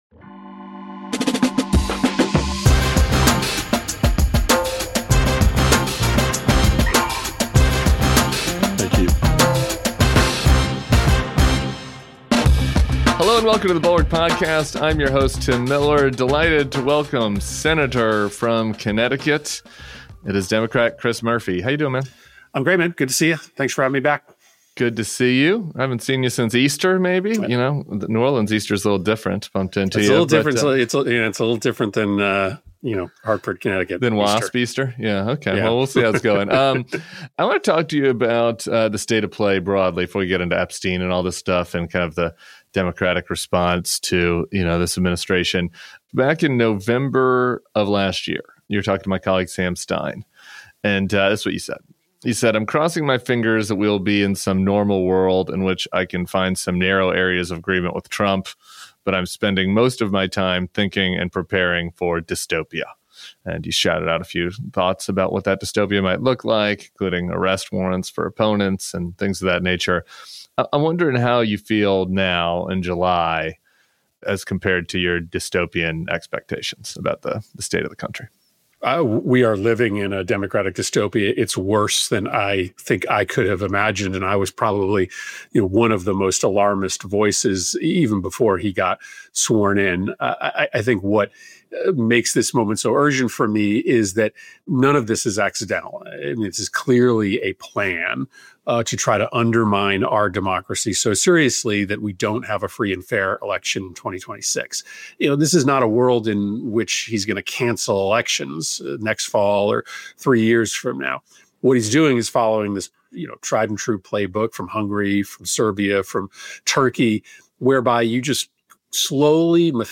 Sen. Chris Murphy joins Tim Miller. show notes Sen.